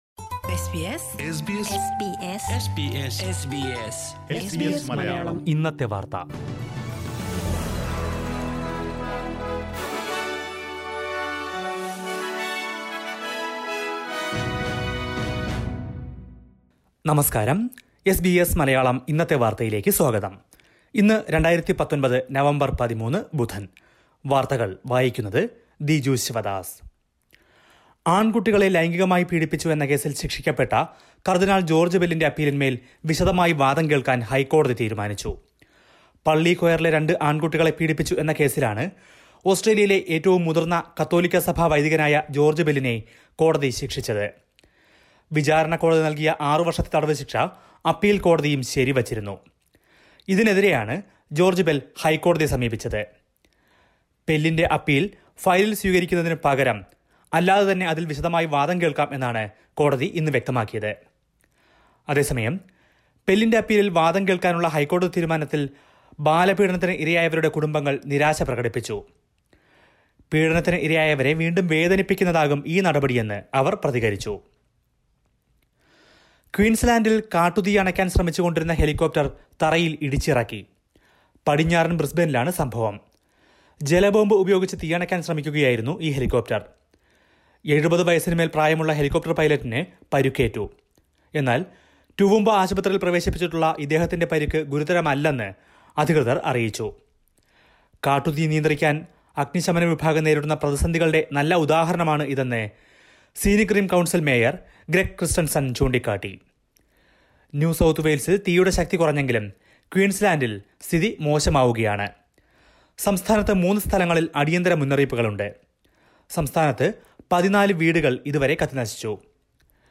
2019 നവംബർ 13ലെ ഓസ്ട്രേലിയയിലെ ഏറ്റവും പ്രധാന വാർത്തകൾ കേൾക്കാം.